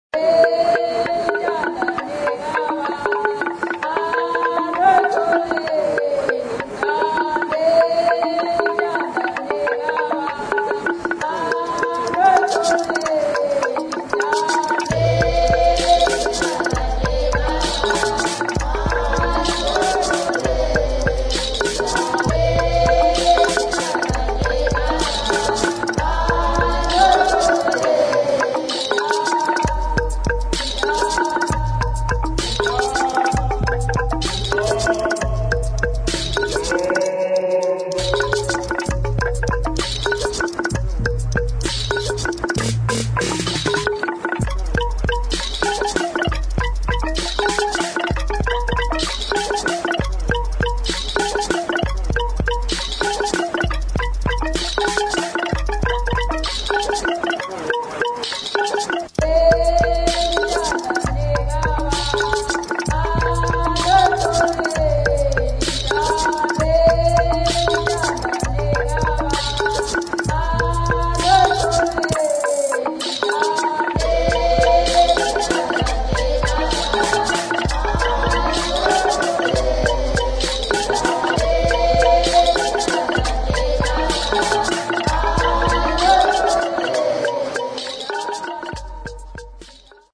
[ AFRO / GHETTO / HOUSE / EXPERIMENTAL ]